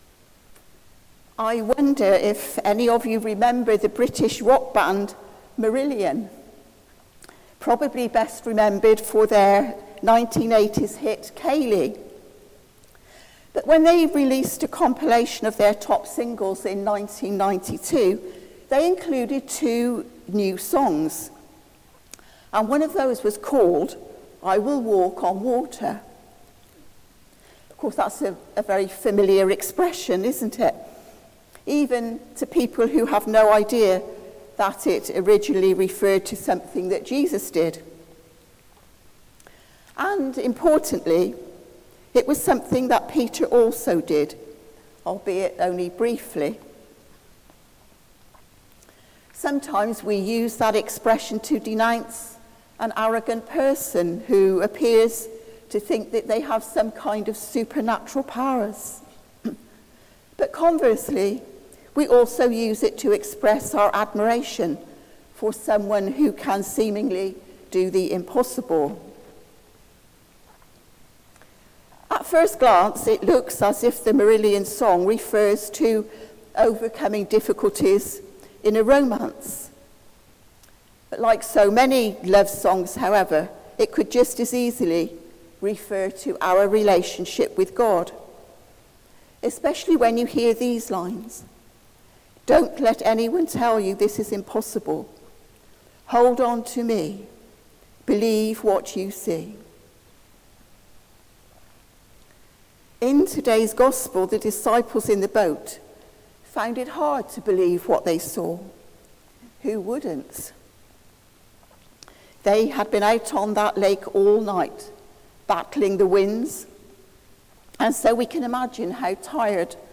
Sermon: Fix Your Eyes Upon Jesus | St Paul + St Stephen Gloucester